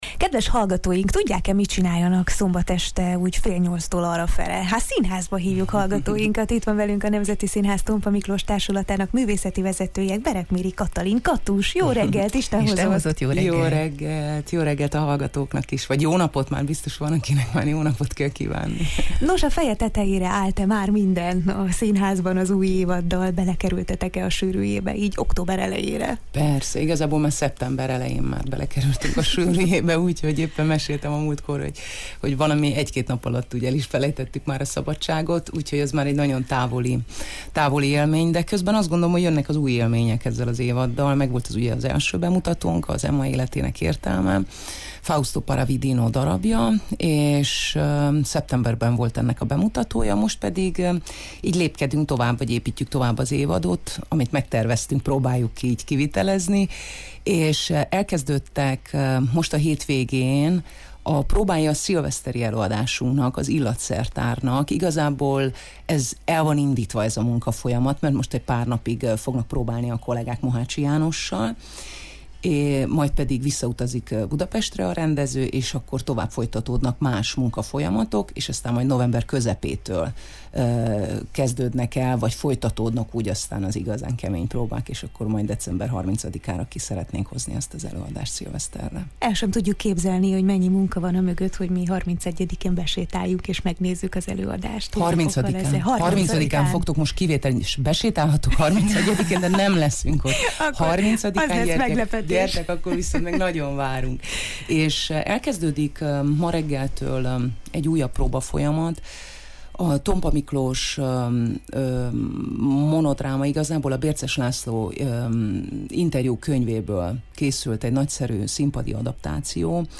A Marosvásárhelyi Nemzeti Színház Tompa Miklós Társulatával való együttműködésünk egyik eredménye az a beszélgetés-sorozat, melyre hétfőről héfőre számíthatnak a hallgatók a Jó reggelt, Erdéyl!-ben.